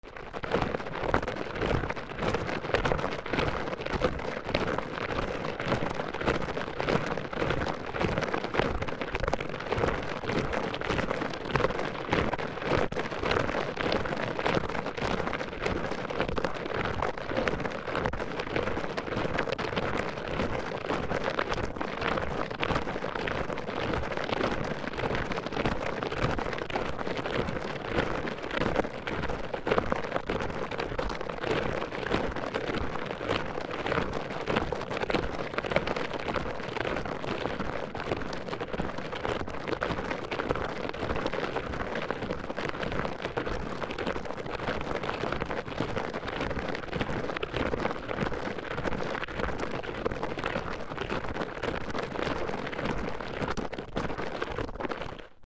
土をならす(靴)
/ I｜フォーリー(足音) / I-240 ｜足音 特殊1